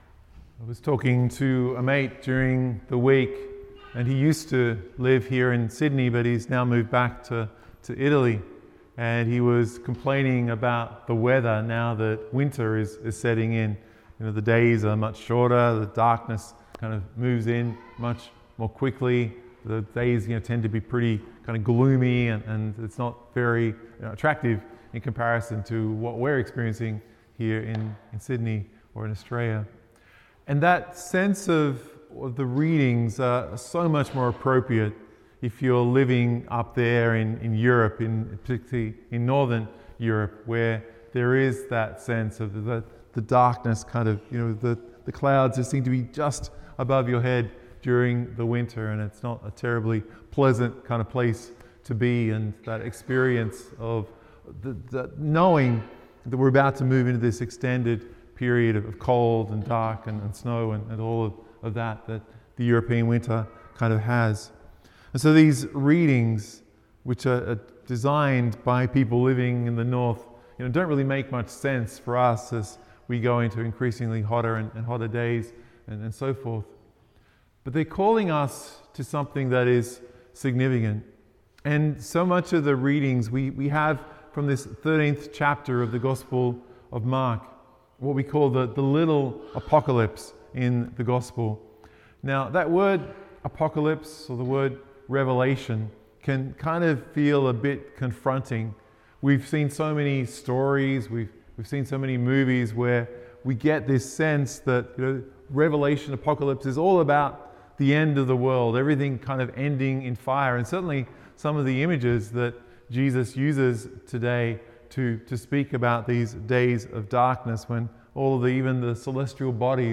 Download or Play MP3 MP3 media (Vigil)